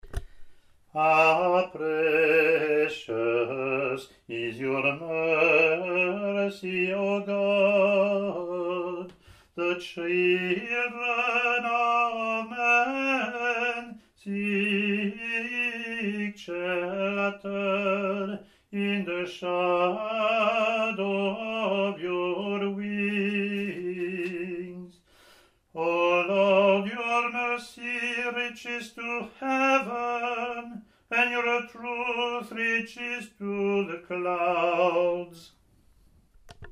English antiphon – English verse
ot24-comm-eng-pw.mp3